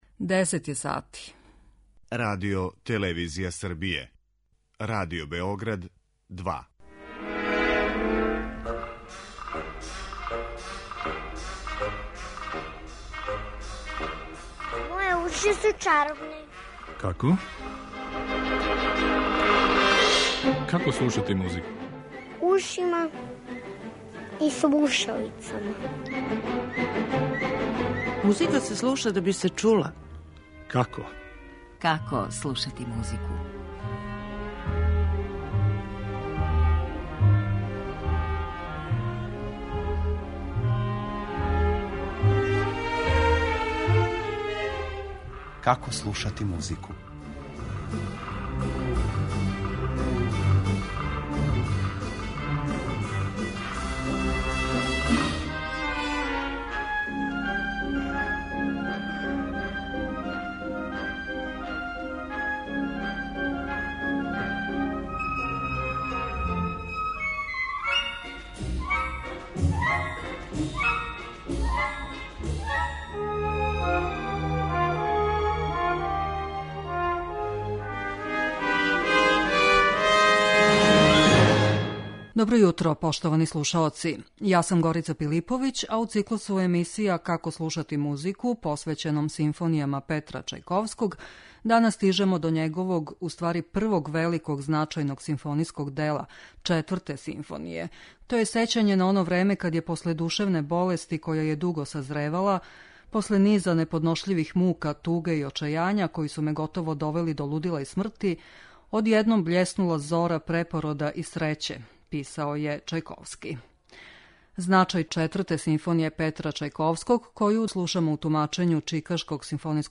У августовском циклусу емисија Како слушати музику, посвећеном симфонијама Петра Чајковског, данас стижемо до његовог првог великог, значајног симфонијског дела – Четврте симфоније ‒ коју слушамо у тумачењу Чикашког симфонијског оркестра под управом Ђерђа Шолтија.